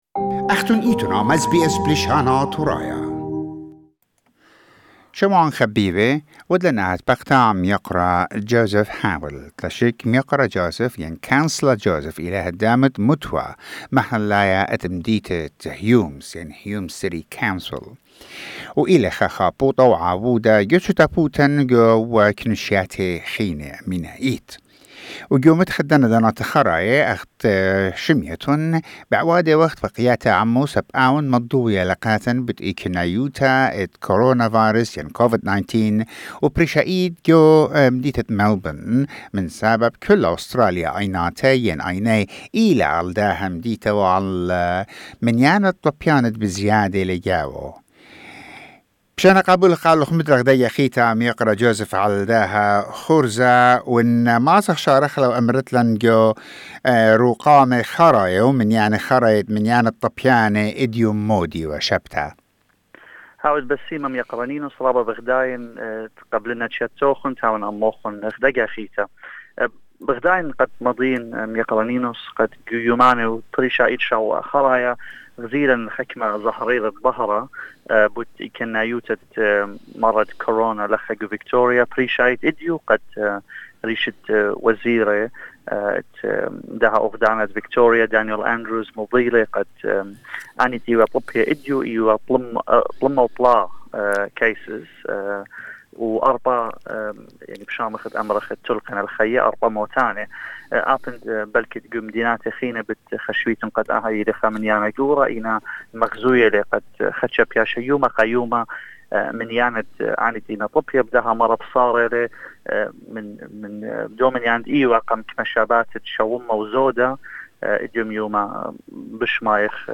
Councillor Joseph Haweil from the Hume city Council in Melbourne talks about the latest developments regarding COVID-19 Pandemic in Victoria